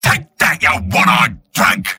Robot-filtered lines from MvM.